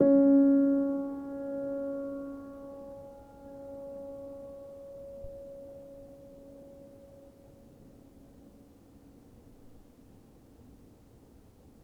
healing-soundscapes/Sound Banks/HSS_OP_Pack/Upright Piano/Player_dyn1_rr1_020.wav at main